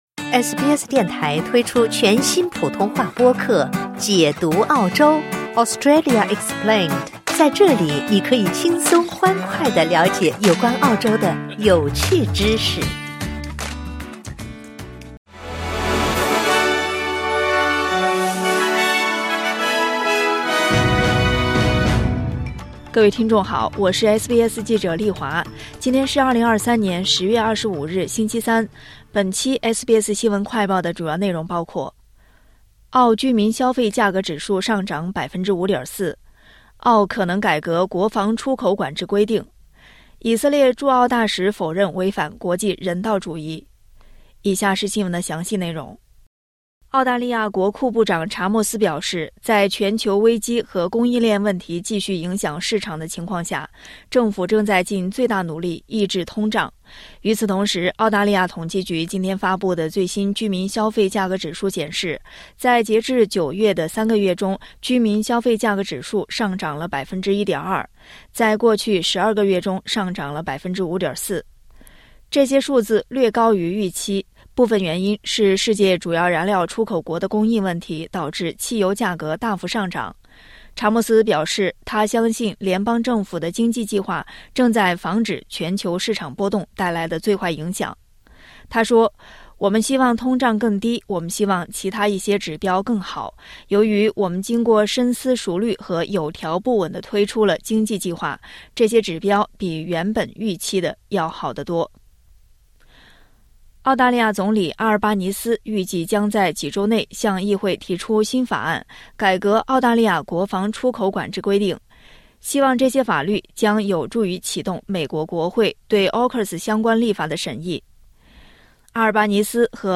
【SBS新闻快报】澳居民消费价格指数上涨5.4%